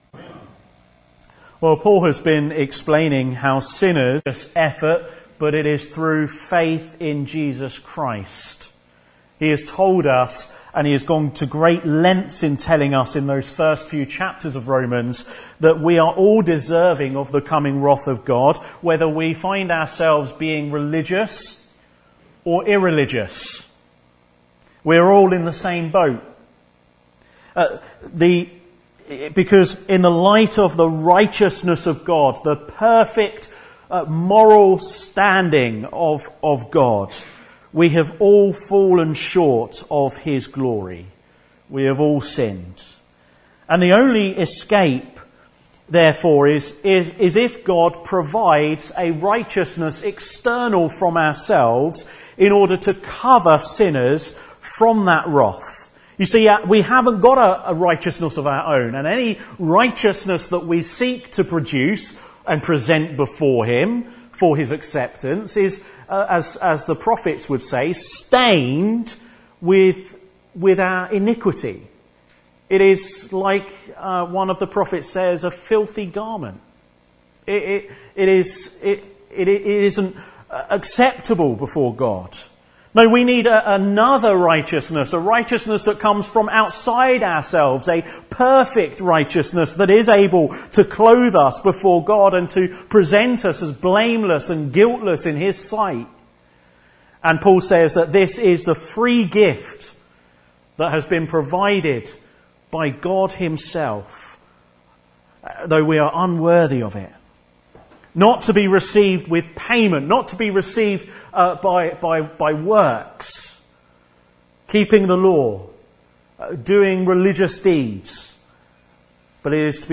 5-10 Service Type: Afternoon Service Special Service Did Jesus Really Rise From the Dead?